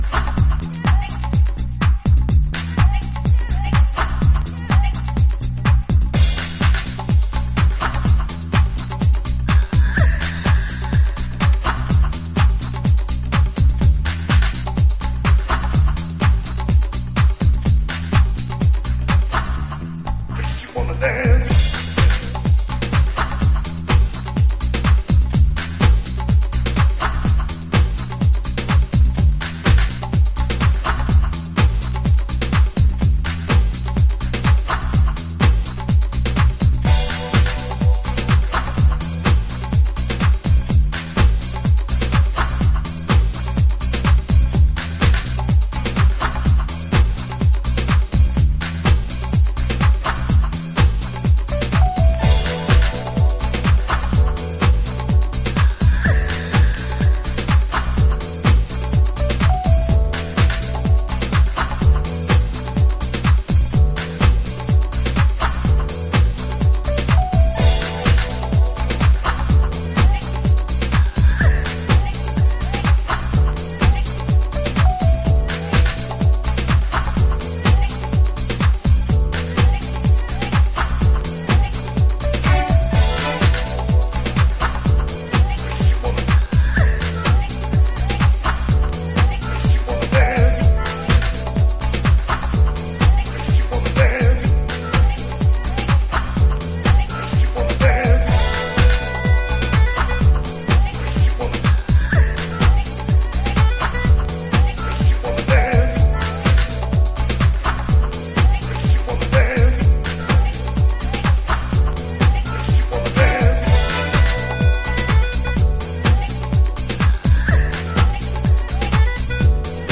わかっていても足がソワソワするビートに定番のヴォイスサンプルやアコースティックのウォーミー塩梅がバッチリです。